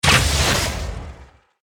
archer_skill_guidedshot_02_swing_a.ogg